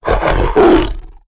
c_sibtiger_bat2.wav